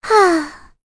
May-Vox_Sigh1.wav